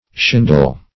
Search Result for " shindle" : The Collaborative International Dictionary of English v.0.48: Shindle \Shin"dle\, n. [See 2d Shingle .]